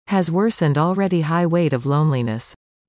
以下、設問１）〜４）の不正解答案文を音読したネイティブ音声を出題しました。
不正解答案の読み上げ音声
▶「rates」と「weight 」：冒頭・語尾の音の違いに注意。
1.has_worsened_already_high_weig.mp3